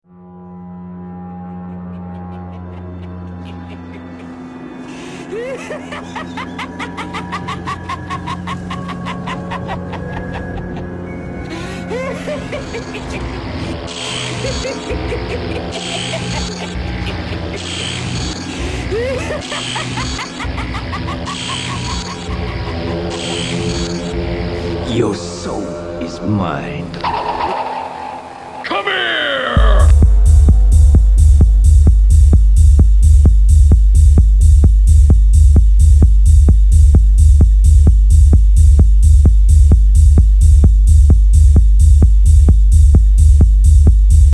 Hab mal in ner Live-Session was aufgenommen.... klingt halt ziemlich...Live :-) ( da ich halt einfach nicht weiß, wie man arrangiert, obwohl ich seit...